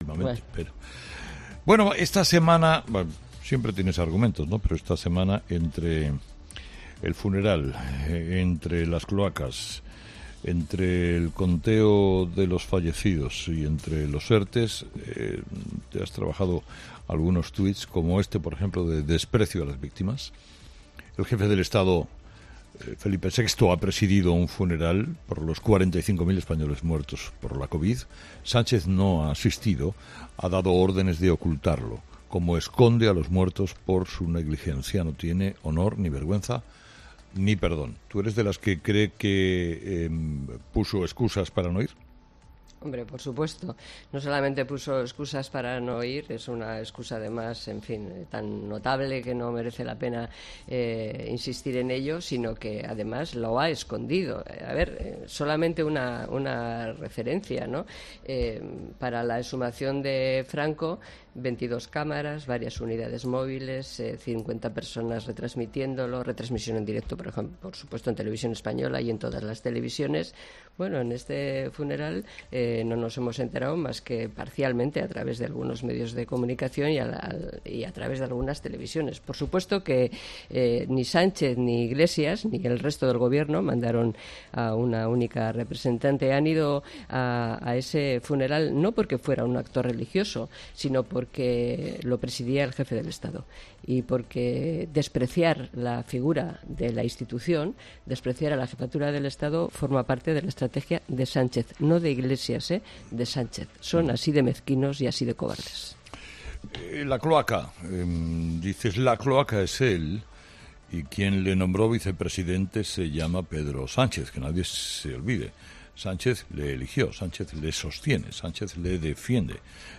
La colaboradora de COPE y fundadora de UPyD, Rosa Díez, ha pasado por los micrófonos de 'Herrera en COPE' para analizar la actualidad política de la semana en tres tuits.